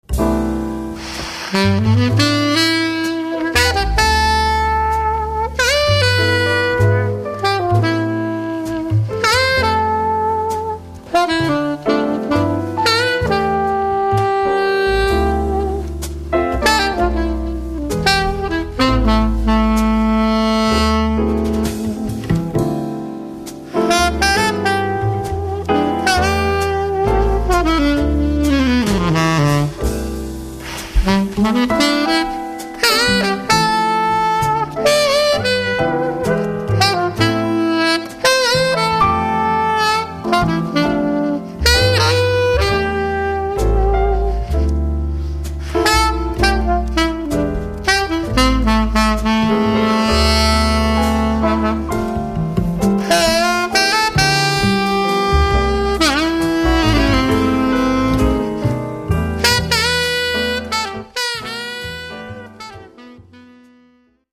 Saxophon
Sax Beispiel 1
sax_only.mp3